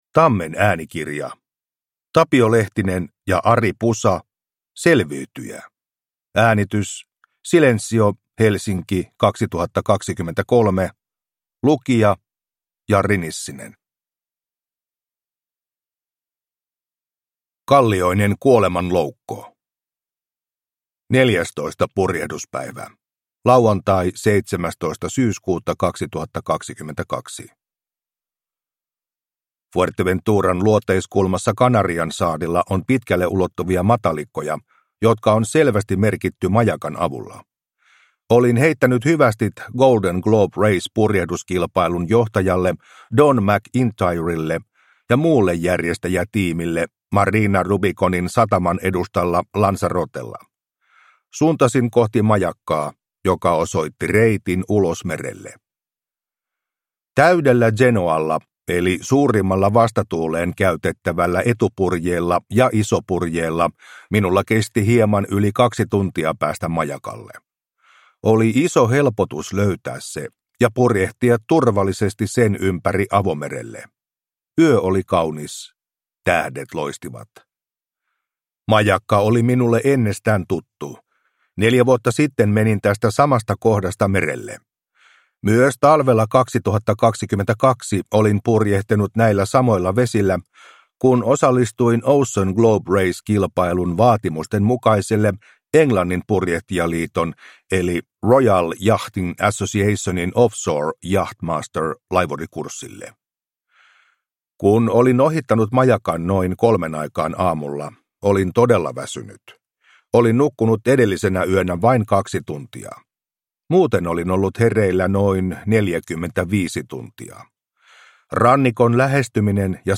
Selviytyjä – Ljudbok – Laddas ner